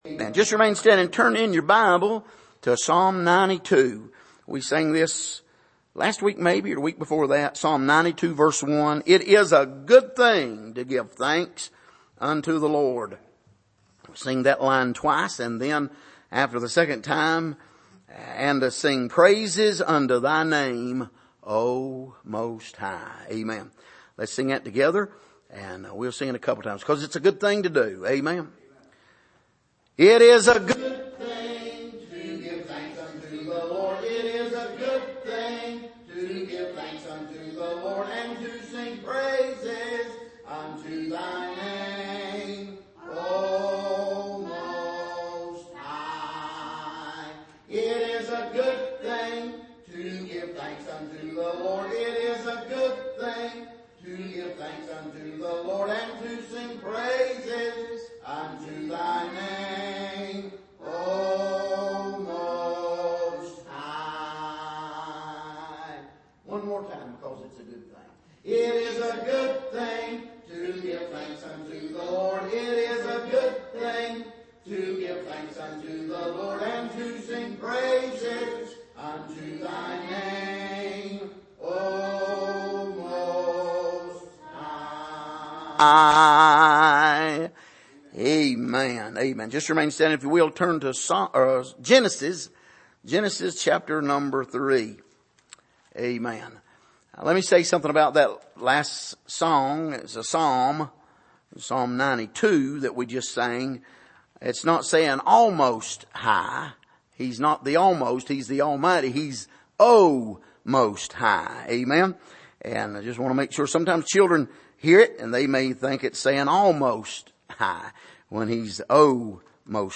Passage: Genesis 3:1-8 Service: Midweek